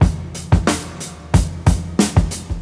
121 Bpm 90's Rock Breakbeat Sample F Key.wav
Free drum groove - kick tuned to the F note. Loudest frequency: 2964Hz
121-bpm-90s-rock-breakbeat-sample-f-key-nEY.ogg